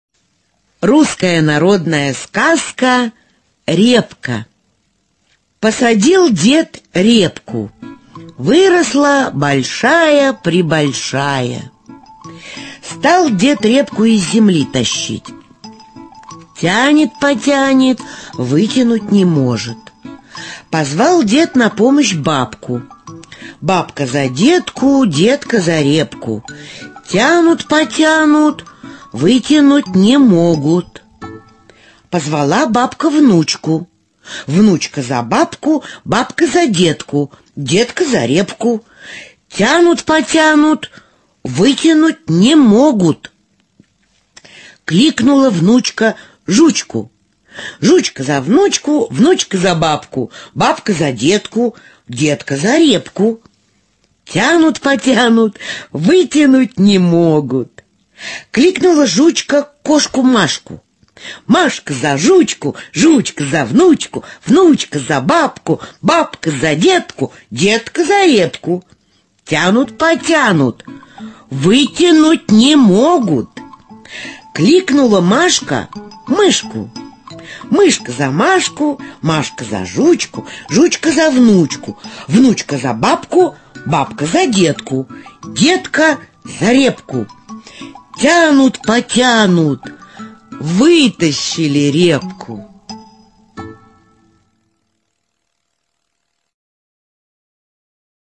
Читаем сказки детям